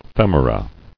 [fem·o·ra]